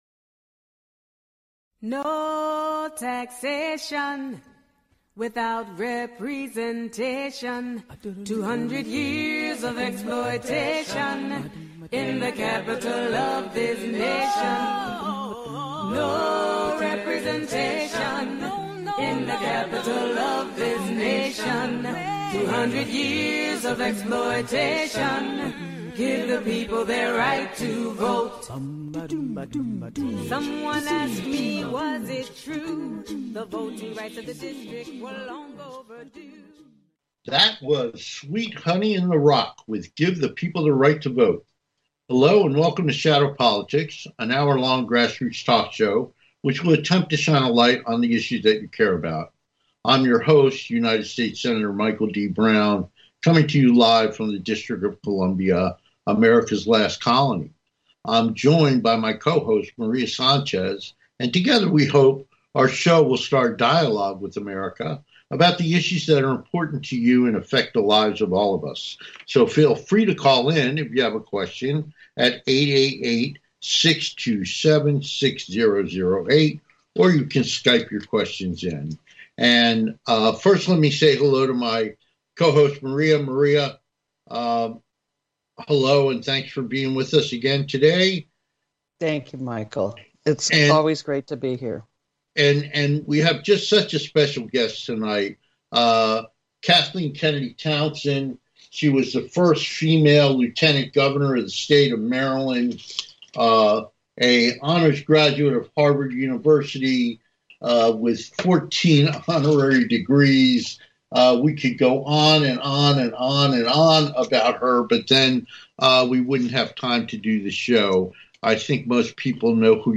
Talk Show Episode, Audio Podcast, Shadow Politics and Guest, Kathleen Kennedy Townsend on , show guests , about Lieutenant Governor,Kathleen Kennedy Townsend,first female Lieutenant Governor,Maryland,American Bridge,Democratic candidates and causes,FAILING AMERICA'S FAITHFUL,God with Politics,Losing Their Way,political climate, categorized as History,News,Politics & Government,Society and Culture
Guest, Kathleen Kennedy Townsend